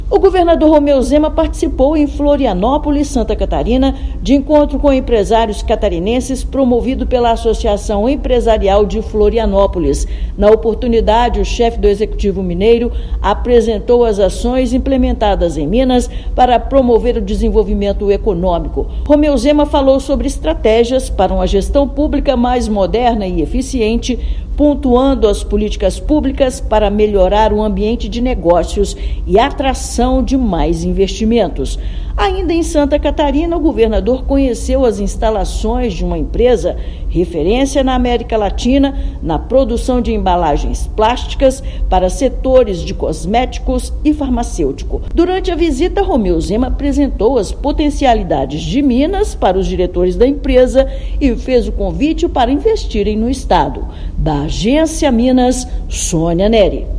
[RÁDIO] Governo de Minas compartilha modelo de gestão da liberdade econômica com o setor produtivo catarinense
Governador apresentou ações adotadas no estado para uma gestão moderna e eficiente durante encontro em Santa Catarina. Ouça matéria de rádio.